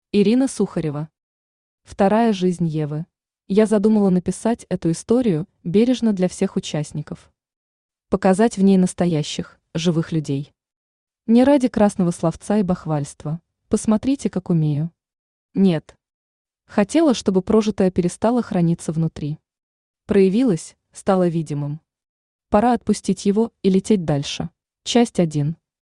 Аудиокнига Вторая жизнь Евы | Библиотека аудиокниг
Aудиокнига Вторая жизнь Евы Автор Ирина Сухарева Читает аудиокнигу Авточтец ЛитРес.